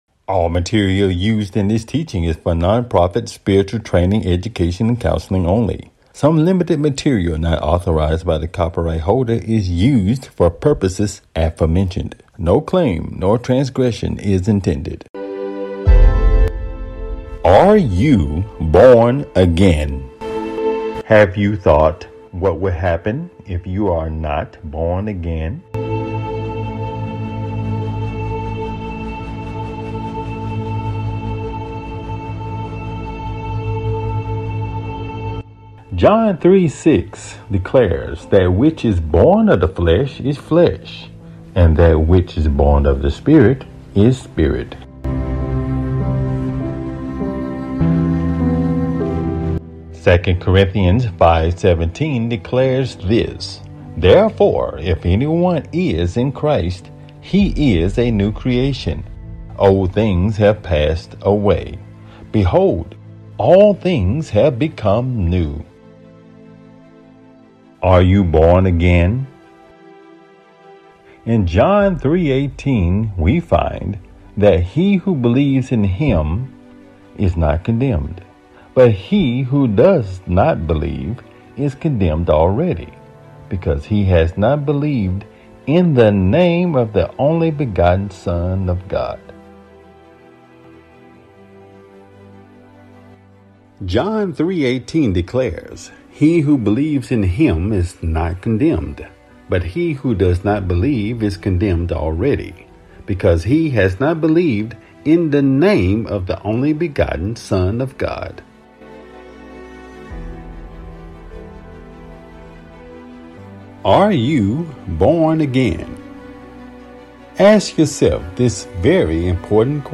4 The Time Has Come Talk Show